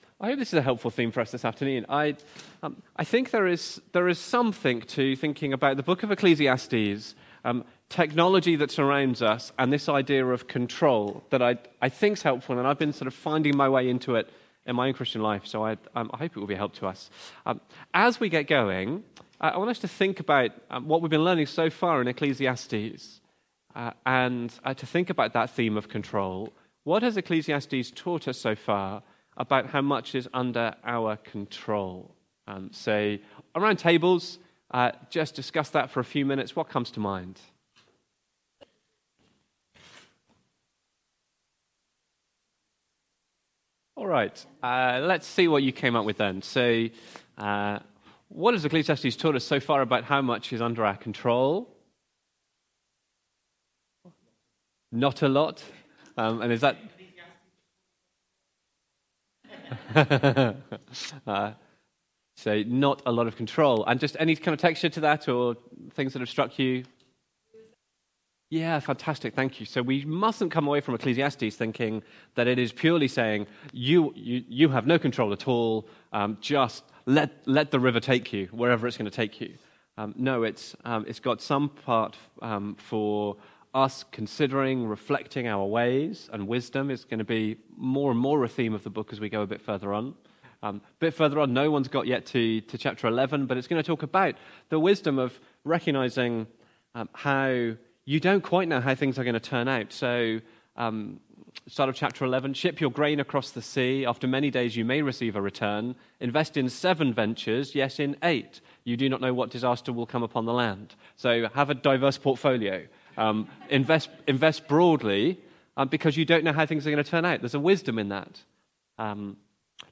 Preaching
Control from the series Following Christ In A Digital World. Recorded at Woodstock Road Baptist Church on 28 September 2025.